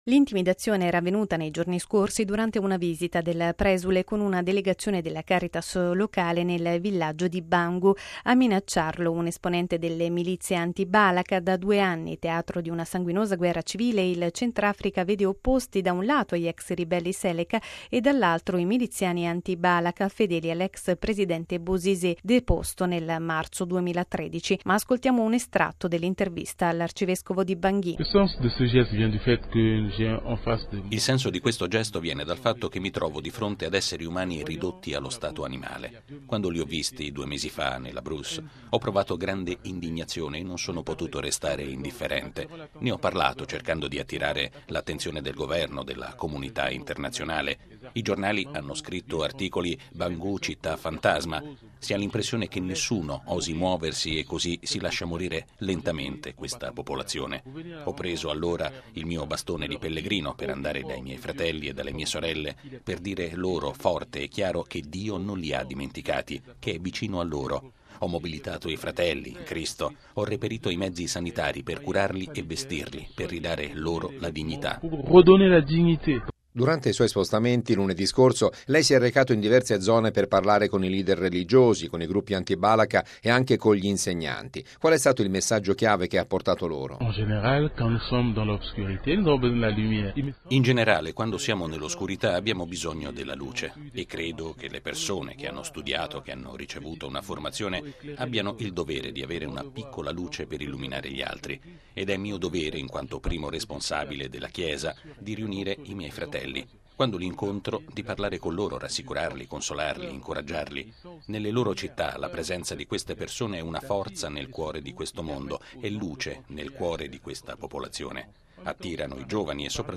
Il servizio
Ma ascoltiamo un estratto dell’intervista dell’arcivescovo di Bangui: